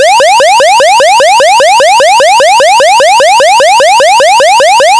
防犯警報音の普及促進
• (1) 基本波形は、矩形波とすること。
サンプル音1 （周波数 500～1,000Hz／周期 毎秒5回）